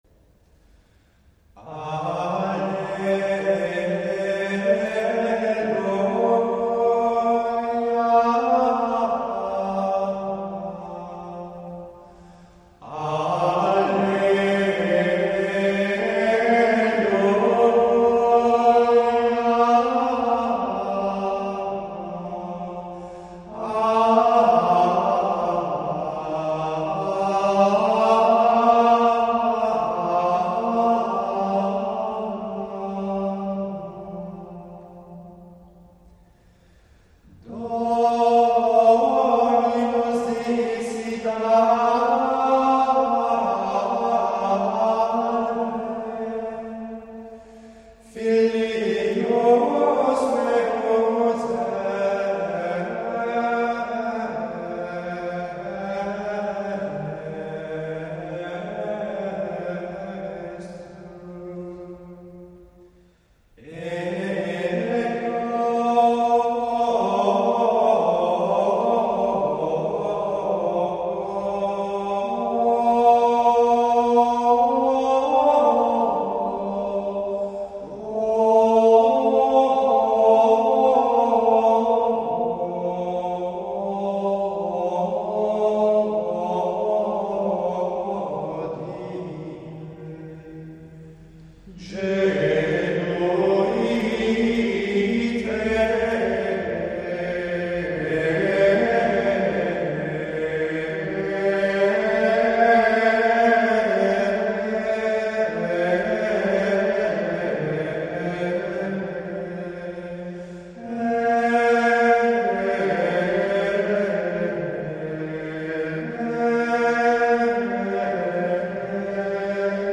Professional recordings of the Proper from the Roman Gradual:
03-alleluja-2.mp3